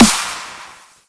Ruffneck_Snare.wav